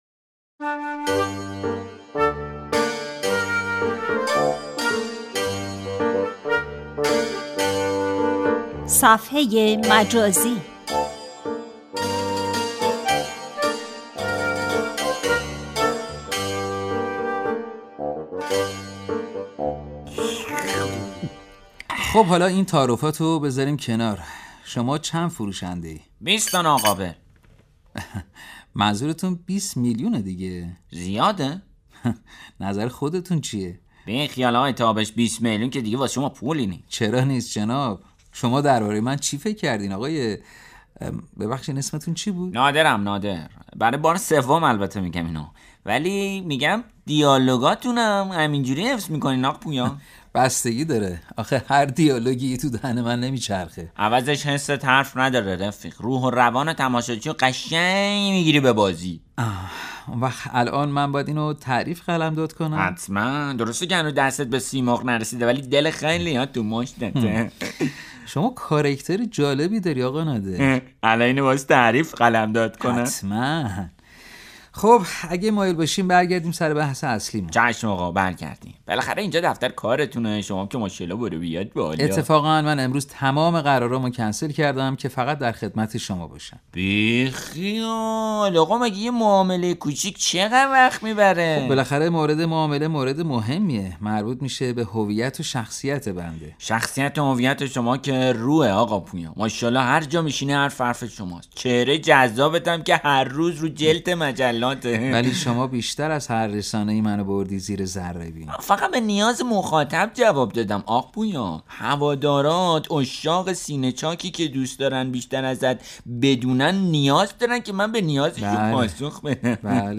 نمایش رادیویی «صفحه مجازی» درباره بازیگری است که یک نفر صفحه ای را با نام وی راه اندازی کرده و حالا می خواهد ۲۰ میلیون تومان به او بفروشد.